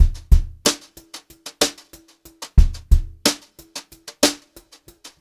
92HRBEAT1 -R.wav